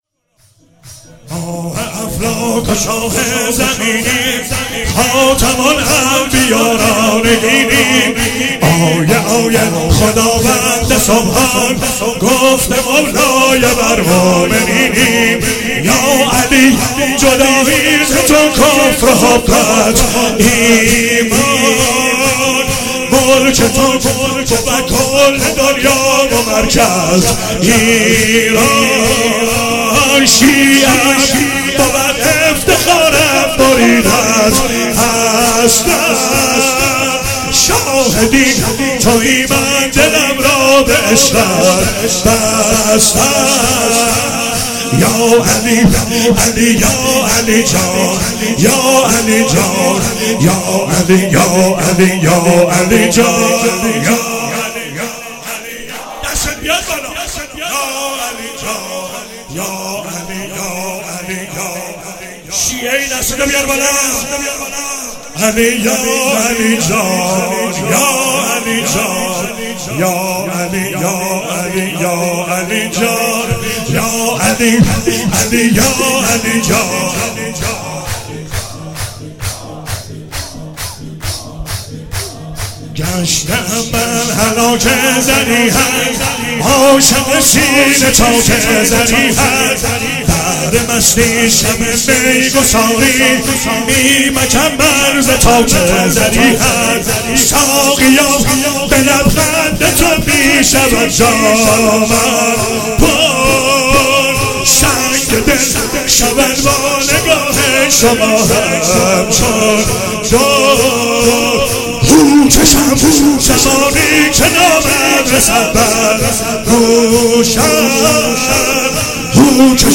شب میلاد امام رضا علیه السلام 96 - شور - ماه افلاک و شاه زمینی
ولادت امام رضا علیه السلام
شور مولودی